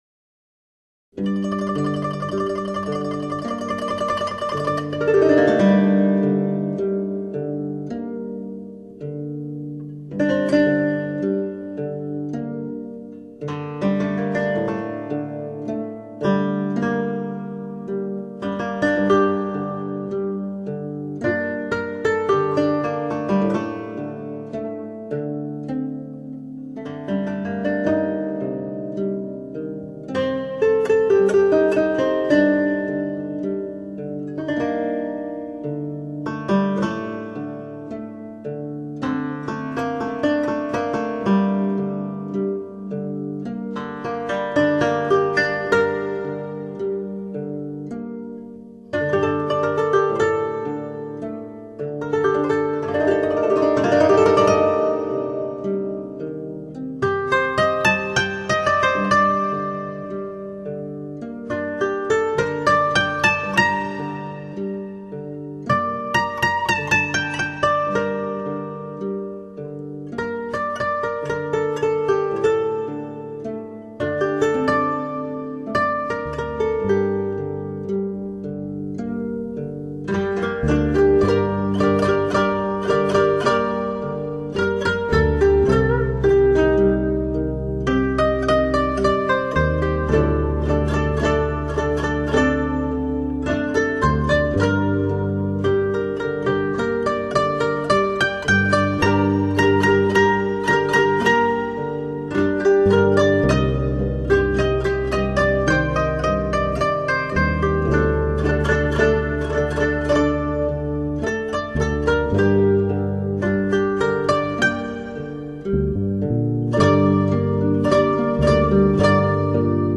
邦楽と洋楽のジョイントコンサート
第１部　　〜〜邦楽〜〜　　１曲目　　河村利夫作曲　　さくら幻想曲